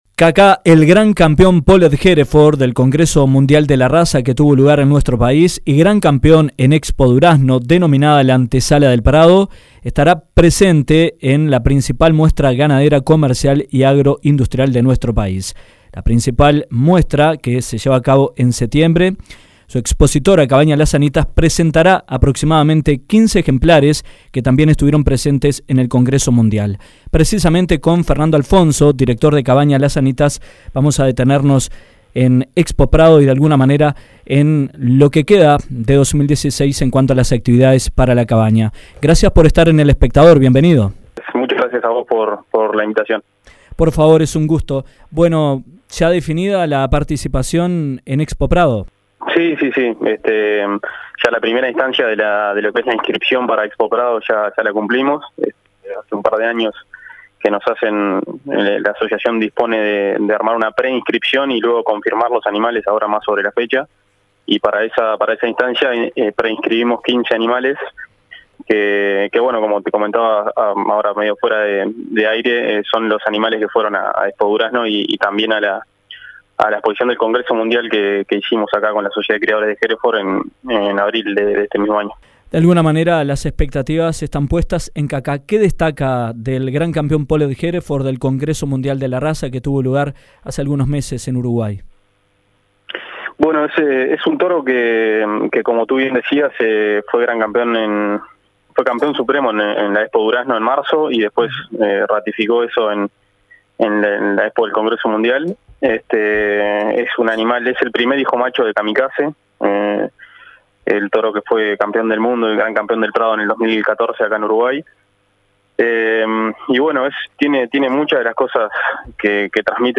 en entrevista con Dinámica Rural dijo que tienen "cifradas expectativas"